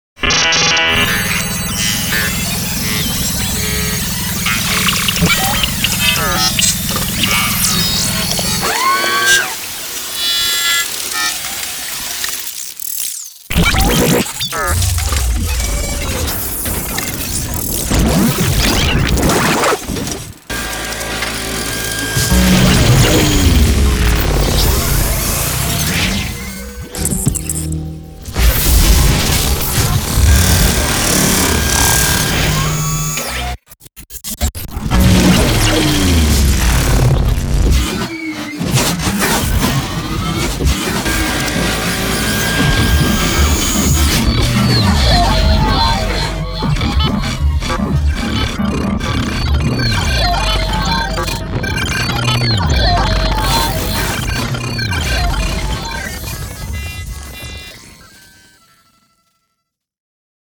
Fx